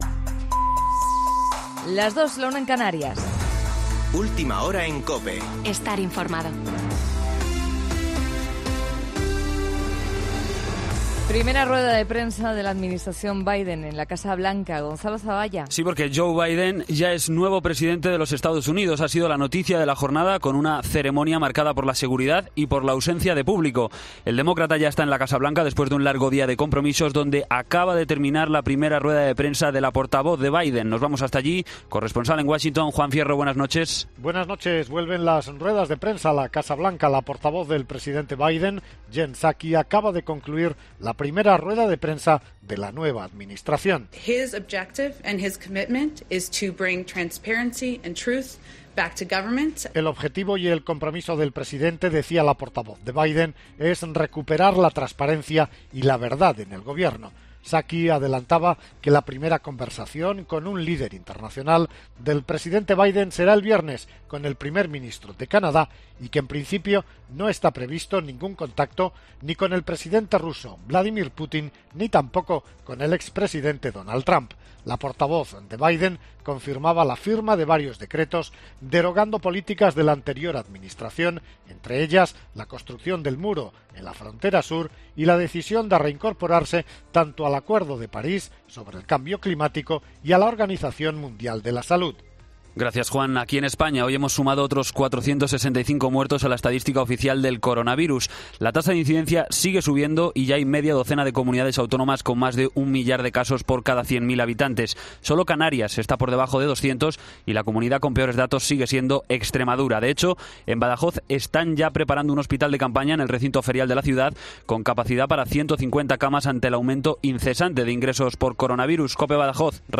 Boletín de noticias COPE del 21 de enero de 2021 a las 02.00 horas